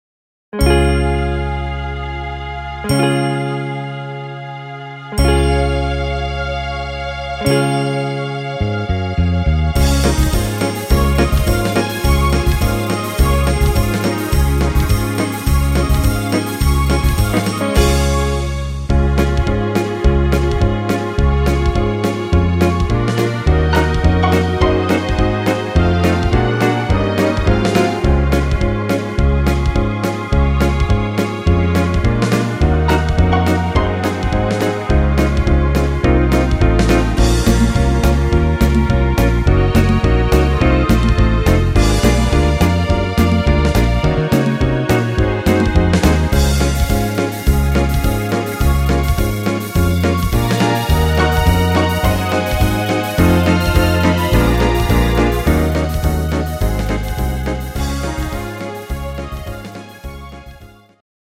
instr. Piano